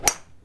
drive1.wav